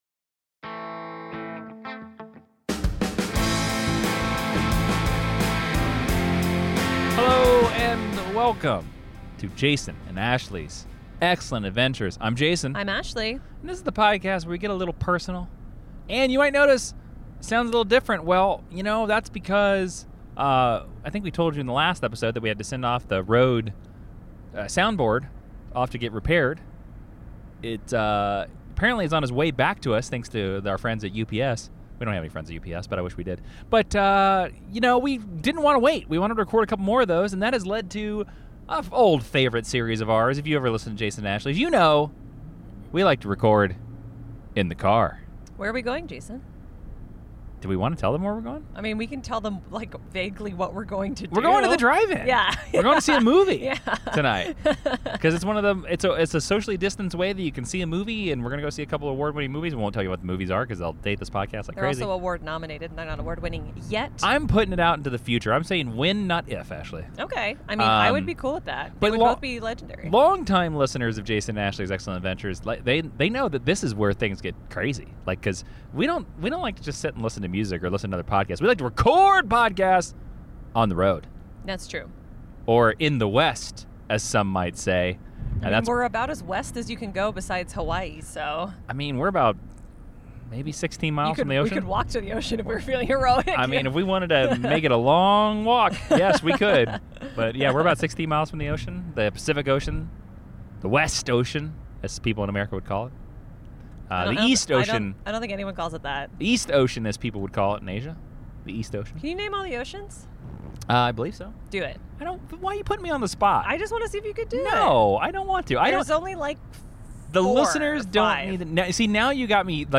This episode is a "RECORDING IN THE CAR" series! This month we are answering a question from a SUPER FRIEND about Why We Love Westerns?